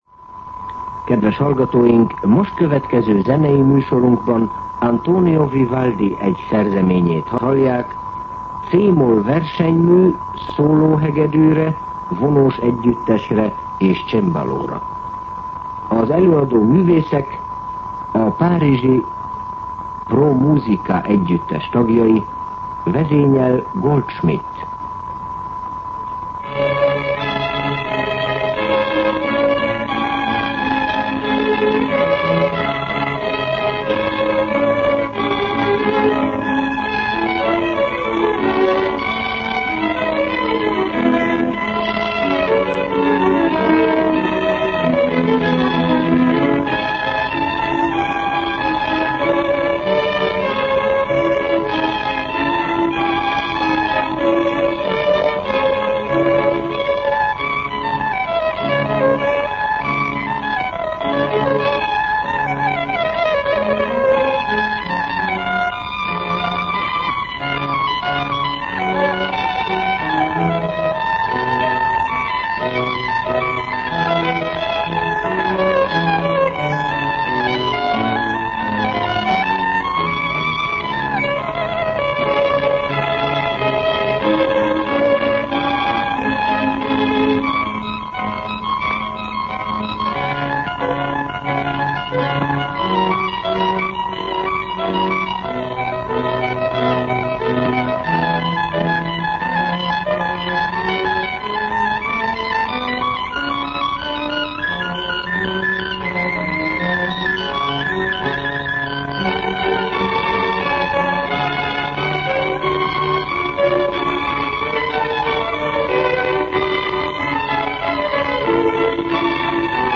Zene
Most következő zenei műsorunkban Antonio Vivaldi egy szerzeményét hallják: C-moll versenymű szólóhegedűre, vonós együttesre és csembalóra. Az előadóművészek a párizsi Pro Musica együttes tagja.